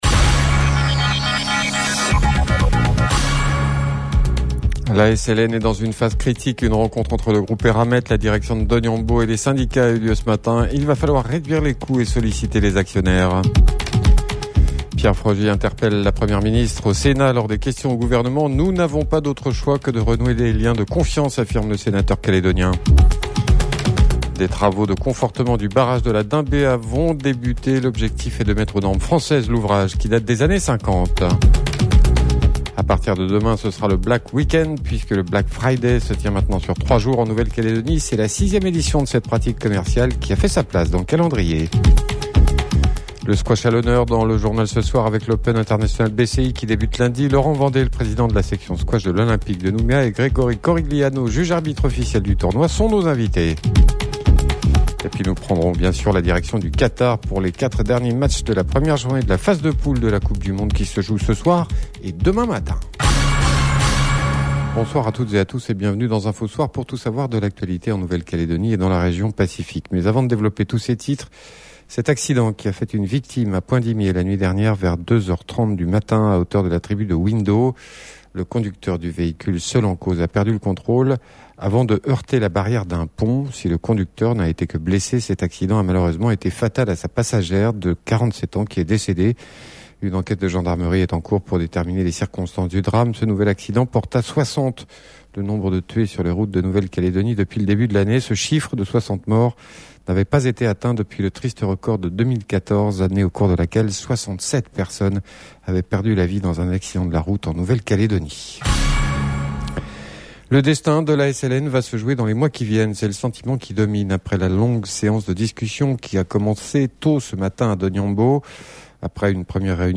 C’est la question posée cette nuit au Sénat par le sénateur Pierre Frogier, alors que Gérald Darmanin et Jean-François Carenco arrivent en Calédonie la semaine prochaine. Vous entendrez Pierre Frogier et la réponse que lui a fait le porte-parole du gouvernement Olivier Véran.